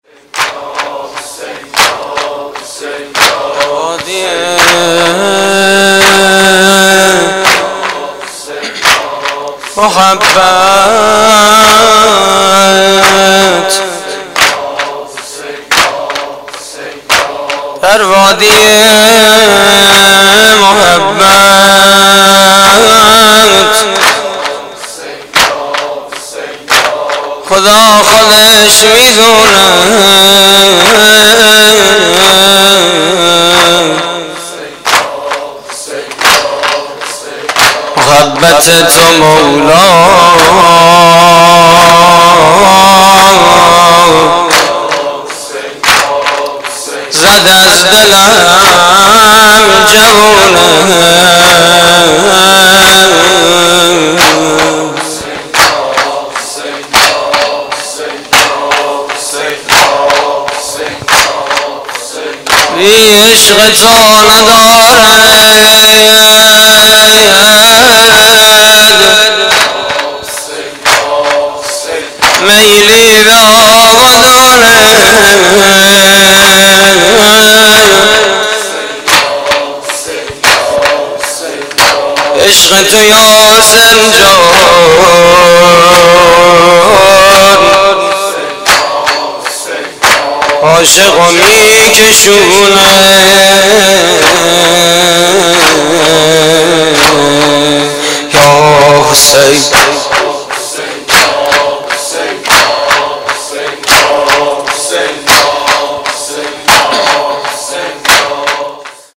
مراسم عزاداری شب چهارم محرم 1432